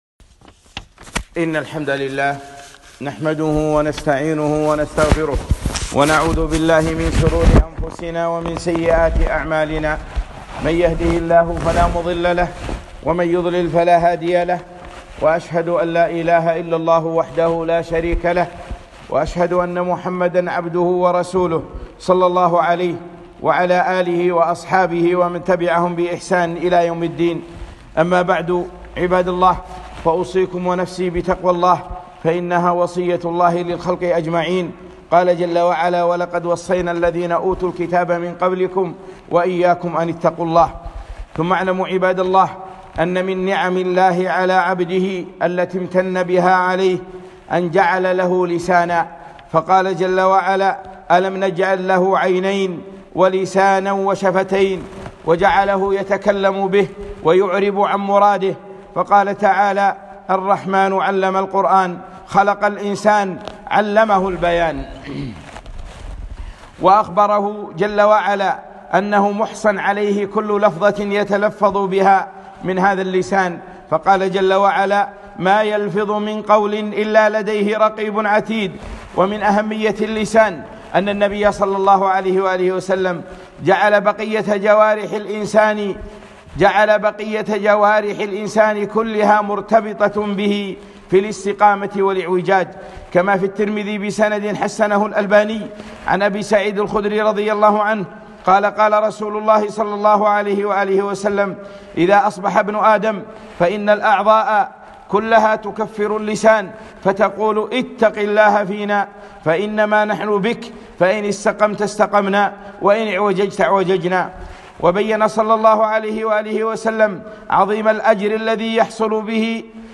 خطبة - نعمة اللسان وخطورته 3-5-1442